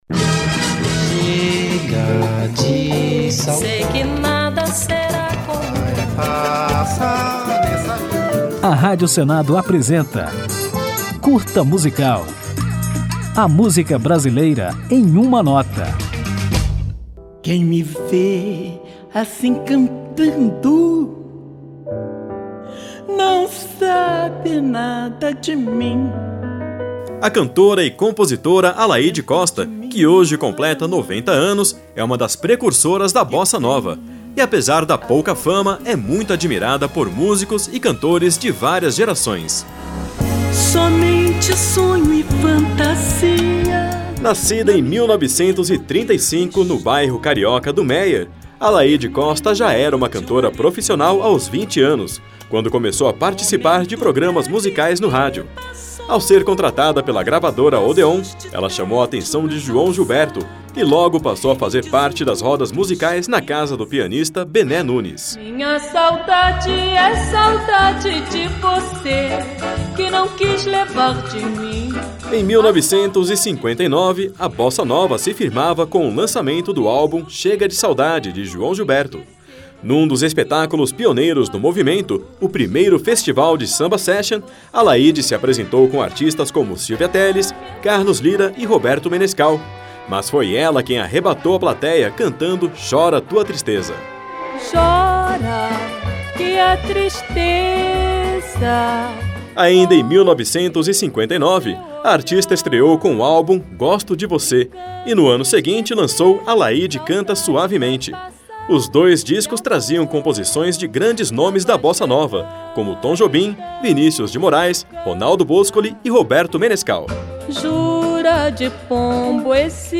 Hoje, comemoramos os 90 anos de Alaíde Costa, cantora e compositora pouco lembrada, que foi uma das vozes que ajudou a Bossa Nova a ganhar o Brasil e o mundo. Depois de conferir um pouco da biografia da artista, vamos homenageá-la ouvindo a música Onde Está Você, lançada por Alaíde Costa em 1965.